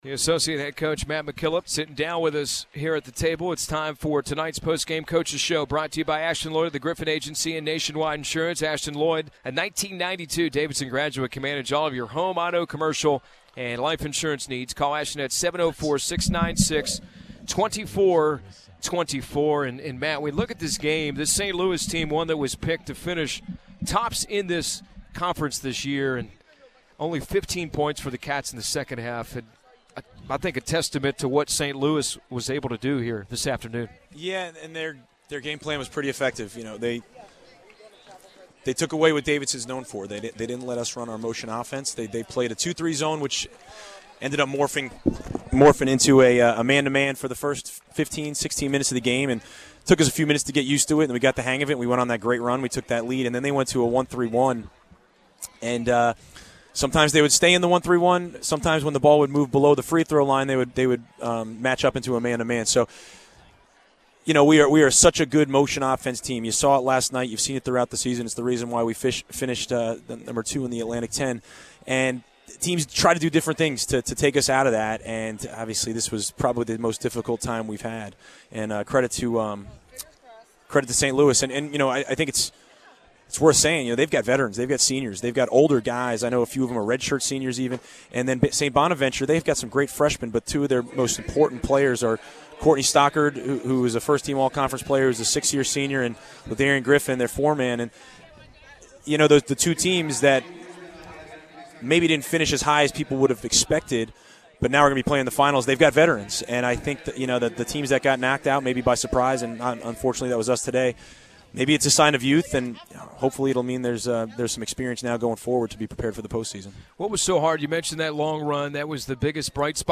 Postgame Radio Interview